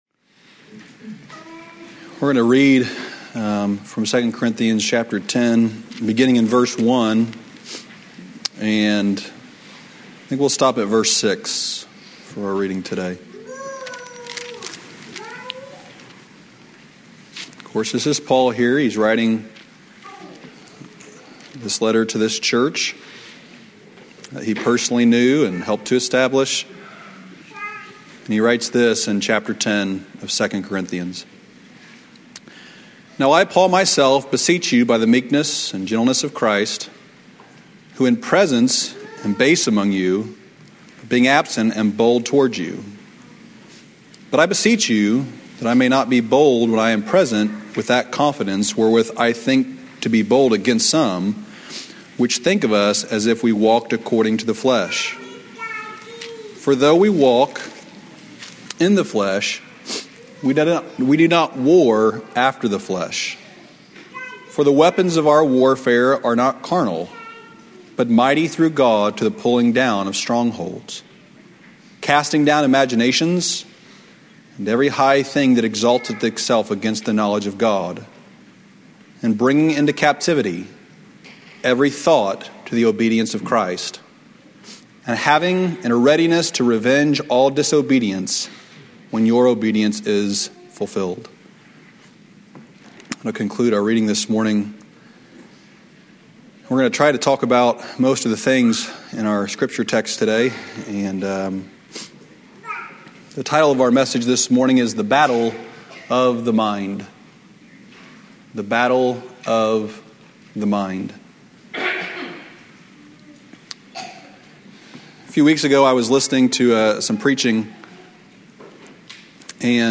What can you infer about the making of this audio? "Drive and Go Forward" 2 Kings 4:22-24 Sunday evening revival sermon from July 21, 2024 at Old Union Missionary Baptist Church in Bowling Green, Kentucky.